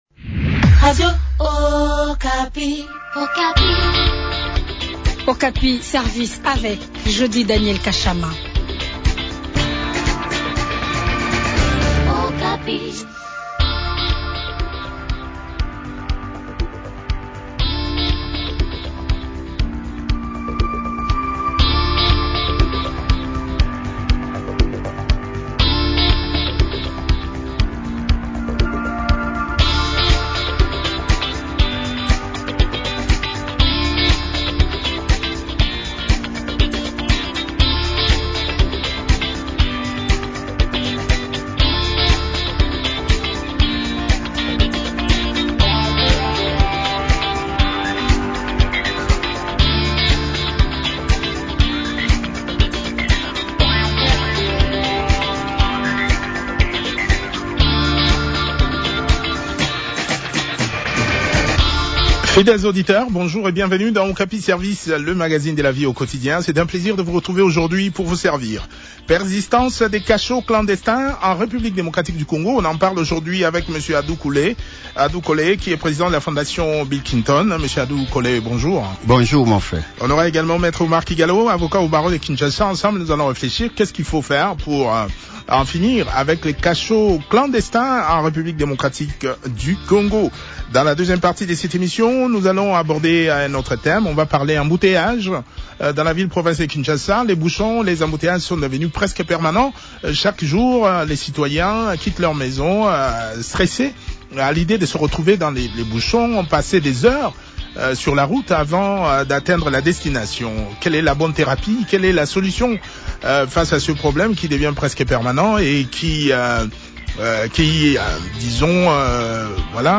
avocat au barreau de Kinshasa/Gombe a également pris part à cette interview.